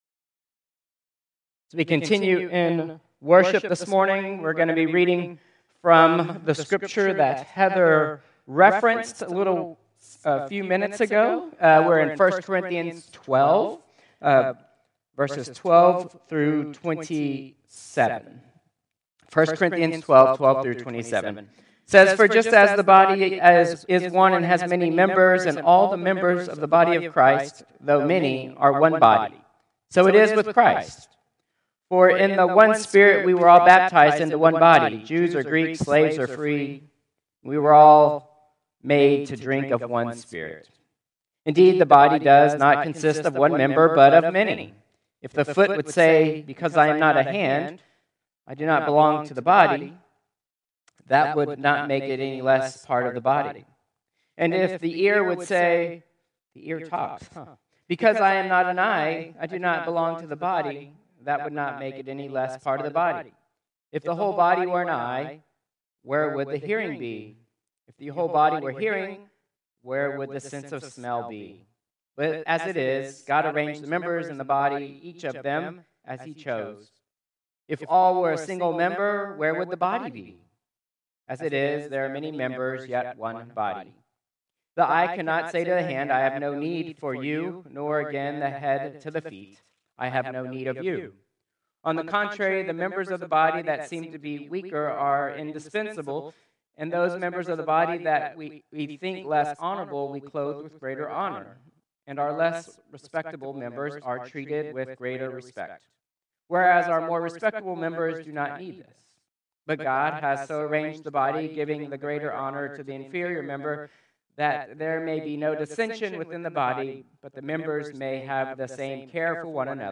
Combined Service 8/28/2025